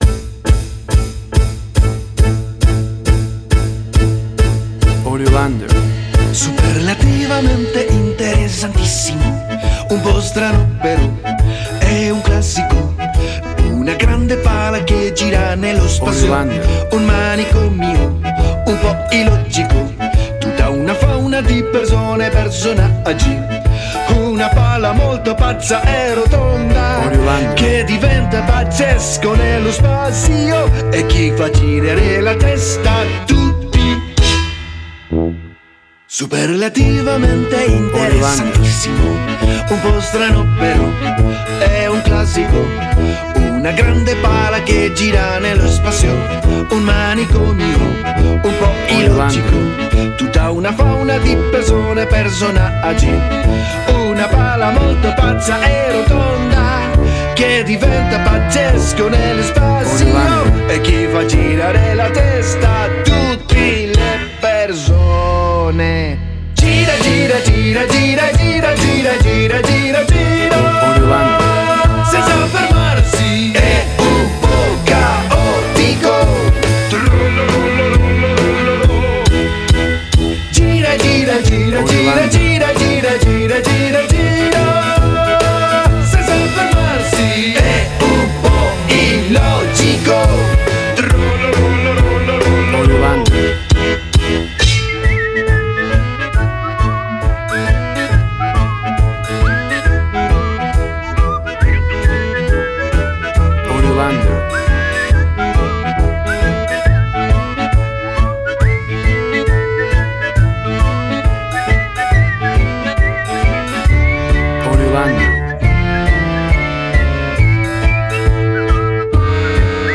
Tempo (BPM): 136